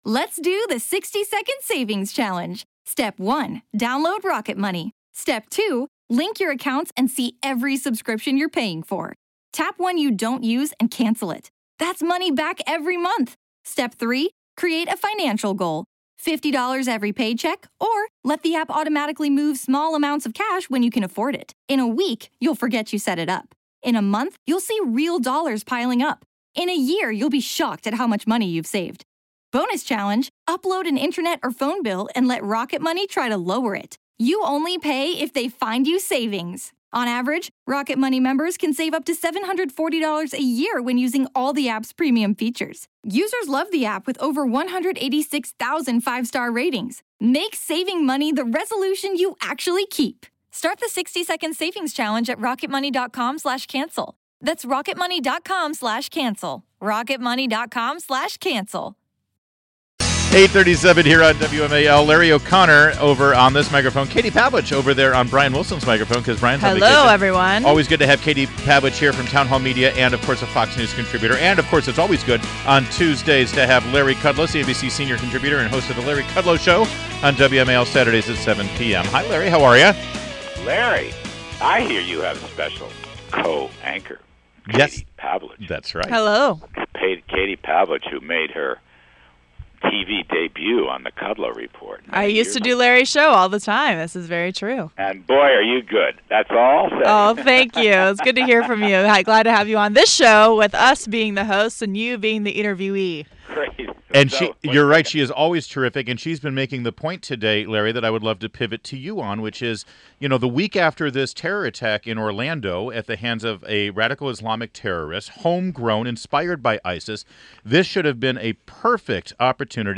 WMAL Interview - LARRY KUDLOW - 06.21.16
INTERVIEW — LARRY KUDLOW- CNBC Senior Contributor and host of The Larry KudlowShow on WMAL Saturdays at 7 pm